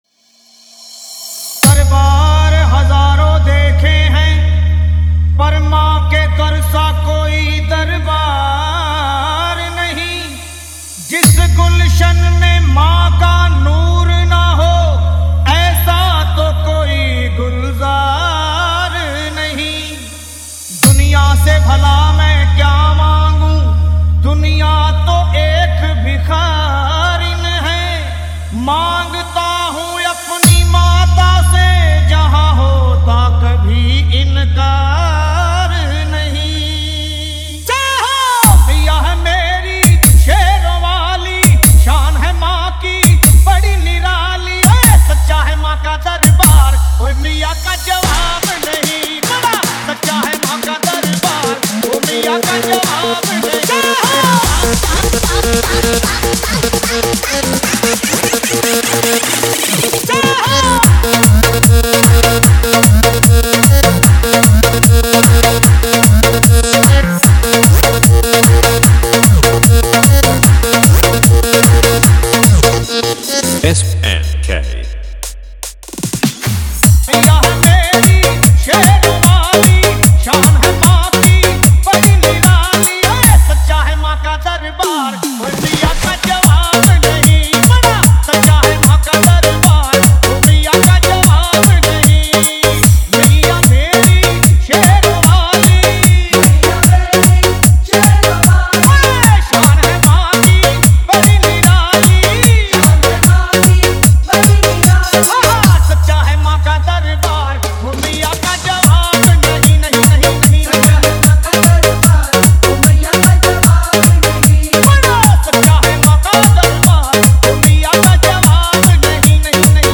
Navratri Dj Remix Song Play Pause Vol + Vol